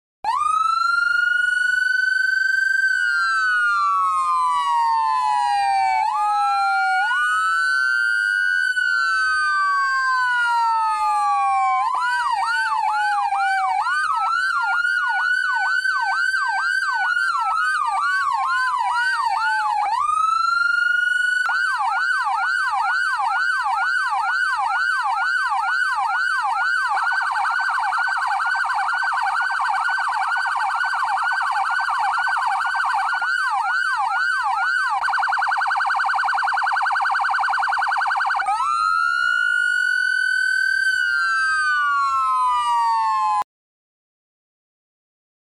xe cảnh sát police siren